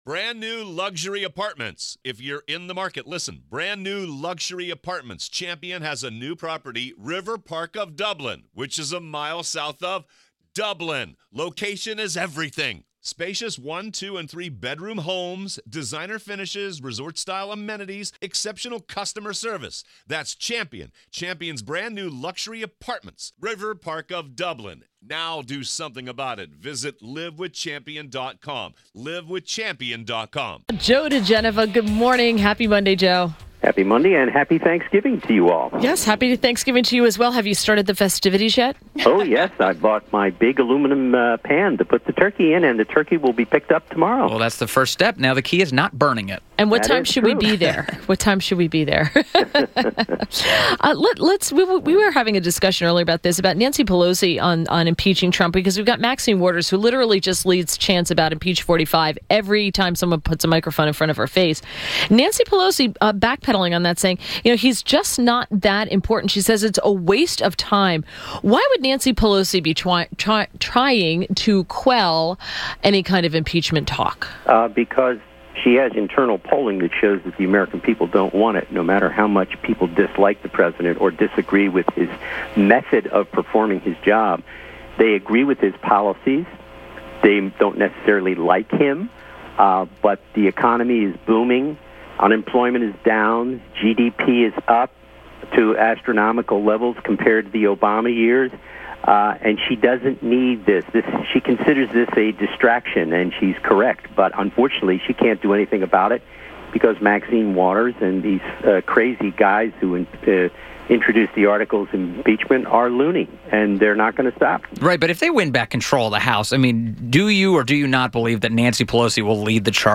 WMAL Interview - JOE DIGENOVA - 11.20.17
INTERVIEW -- JOE DIGENOVA - legal analyst and former U.S. Attorney to the District of Columbia• Joe's thoughts on the slew of sexual harassment claims: Roy Moore... Al Franken, etc• BYRON YORK: FBI, DOJ officials have told congressional investigators they have not been able to...